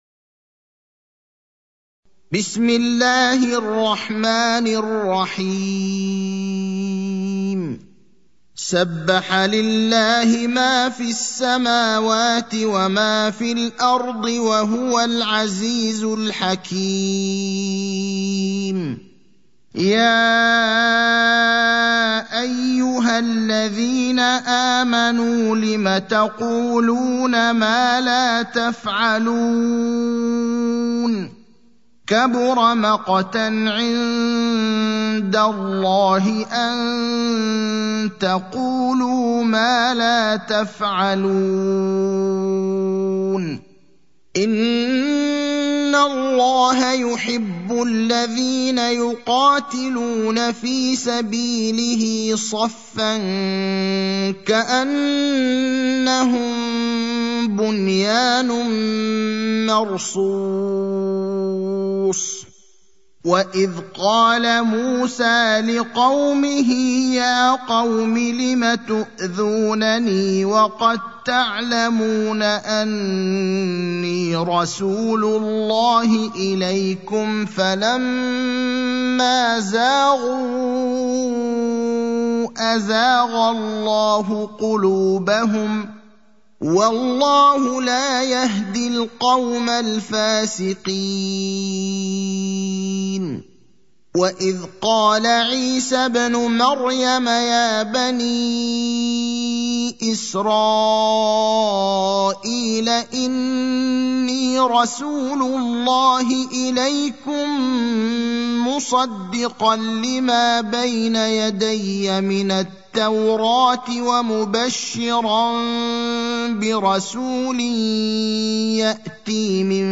المكان: المسجد النبوي الشيخ: فضيلة الشيخ إبراهيم الأخضر فضيلة الشيخ إبراهيم الأخضر الصف (61)